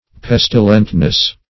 Pestilentness \Pes"ti*lent*ness\, n. The quality of being pestilent.